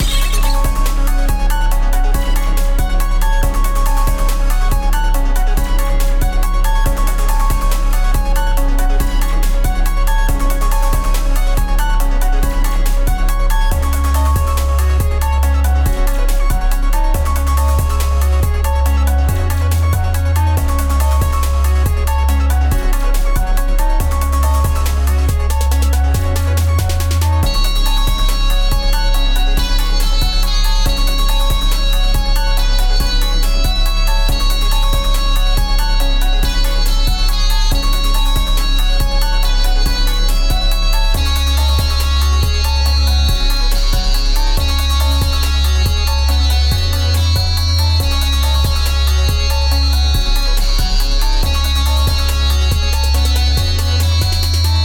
Type BGM